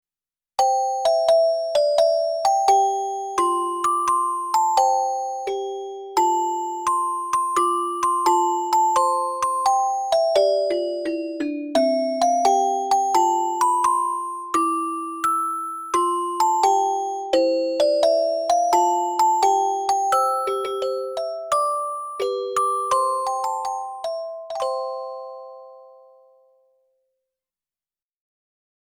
オルゴールVer
nahacitysong_Orgel.mp3